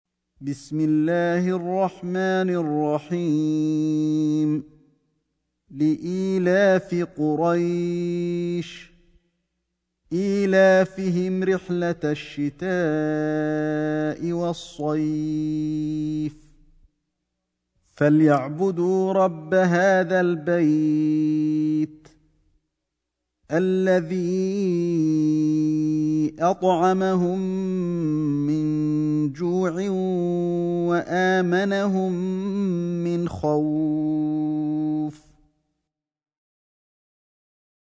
سورة قريش > مصحف الشيخ علي الحذيفي ( رواية شعبة عن عاصم ) > المصحف - تلاوات الحرمين